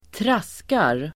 Uttal: [²tr'as:kar]